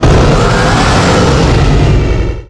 Cri de Primo-Groudon dans Pokémon Rubis Oméga et Saphir Alpha.